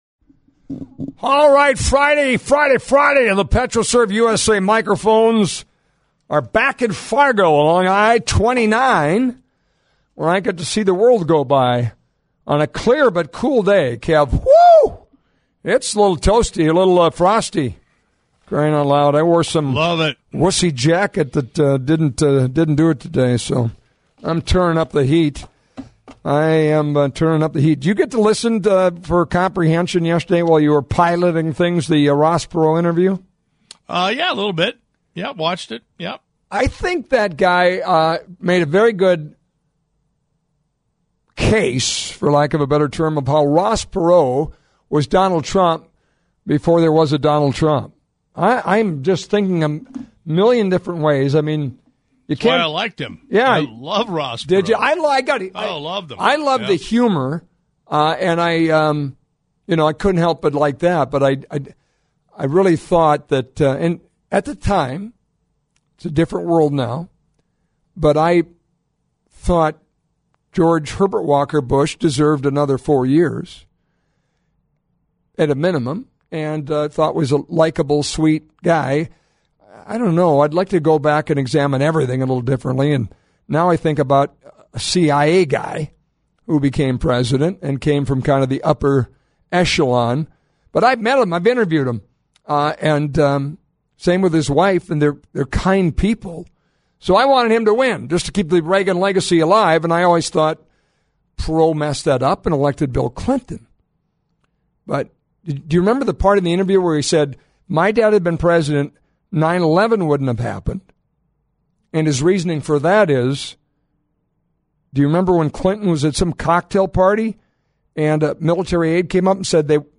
The "What's On Your Mind" crew tackles a mix of sports frustration and political analysis. The episode kicks off with the hosts lamenting the Minnesota Vikings' latest loss, focusing heavily on the offensive line struggles and Carson Wentz's lack of protection.
Shifting gears, the hosts discuss a pre-taped interview with Senator Kevin Cramer.